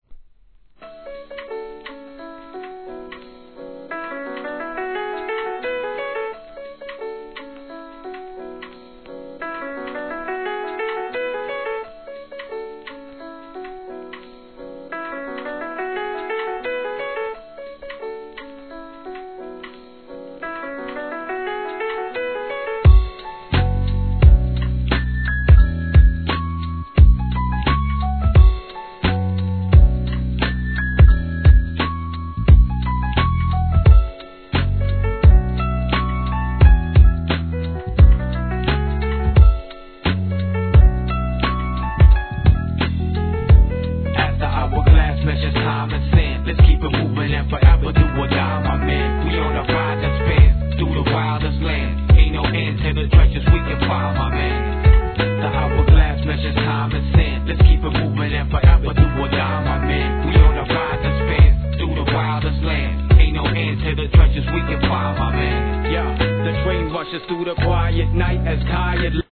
HIP HOP/R&B
極上のジャジー＆メロウなヴァイブスを放つスウェーデン4人組クルー